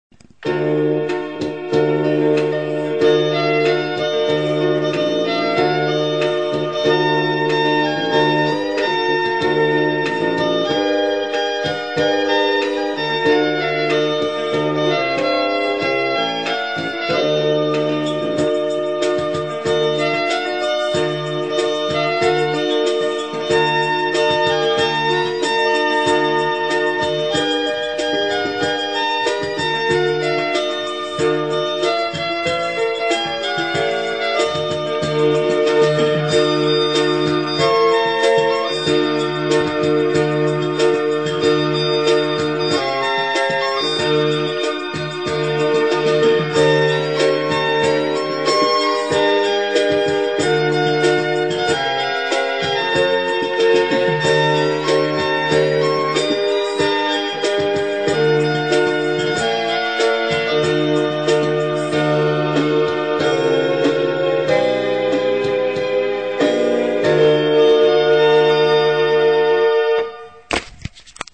עם כל הכבוד "קצת" יצאת מהקצב!!!!